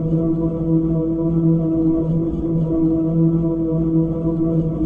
sci-fi_forcefield_hum_loop_08.wav